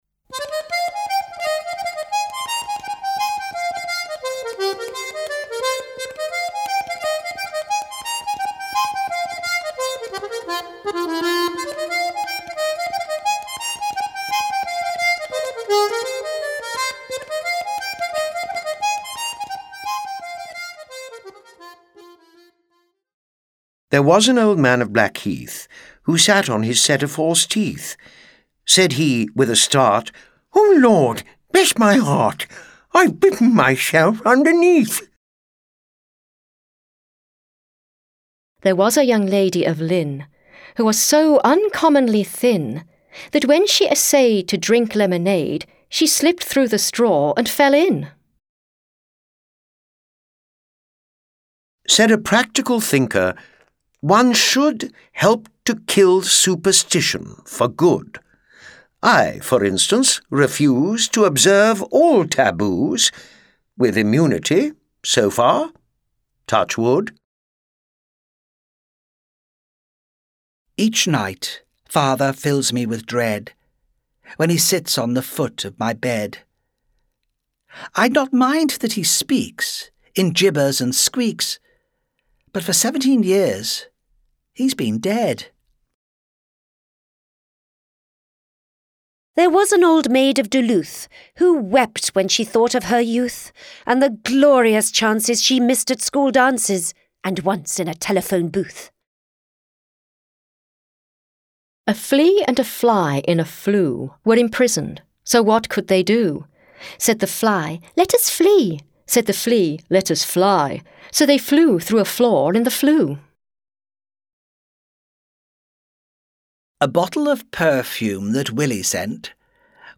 Die von acht englischen Sprechern vorgetragene Sammlung enthält Limericks von bekannten Autoren wie Edward Lear, Lewis Carroll und Robert Louis Stevenson. Die Bandbreite erstreckt sich vom Zungenbrecher bis hin zum Limerick mit philosophischem Hintergrund.